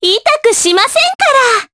Mediana-Vox_Skill4_jp.wav